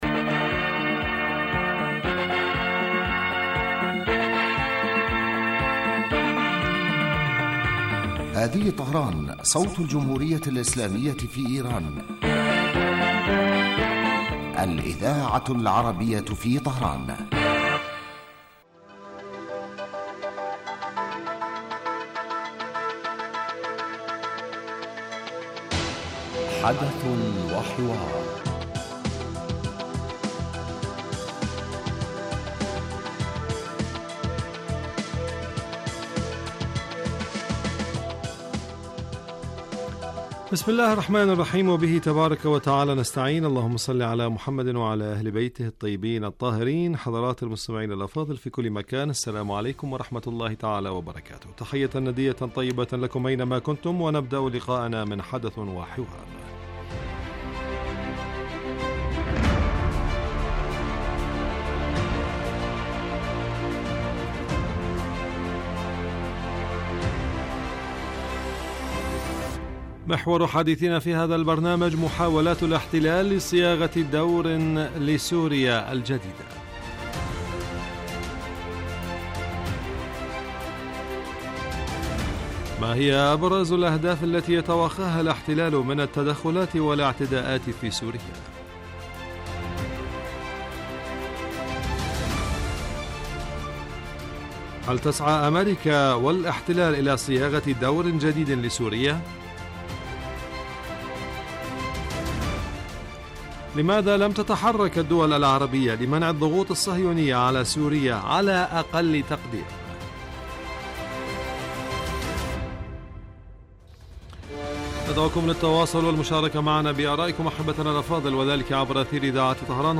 يبدأ البرنامج بمقدمة يتناول فيها المقدم الموضوع ثم يطرحه للنقاش من خلال تساؤلات يوجهها للخبير السياسي الضيف في الاستوديو. ثم يتم تلقي مداخلات من المستمعين هاتفيا حول الرؤى التي يطرحها ضيف الاستوديو وخبير آخر يتم استقباله عبر الهاتف ويتناول الموضوع بصورة تحليلية.